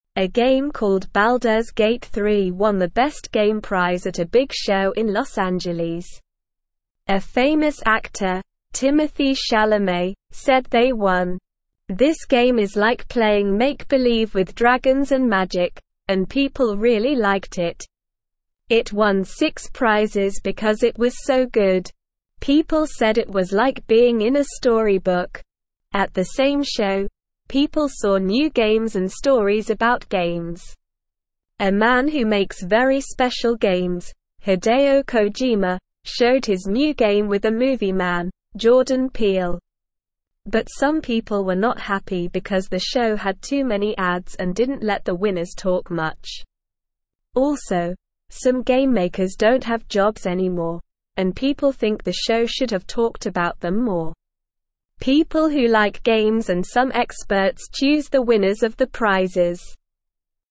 Slow
English-Newsroom-Beginner-SLOW-Reading-Baldurs-Gate-3-Wins-Best-Game-Prize.mp3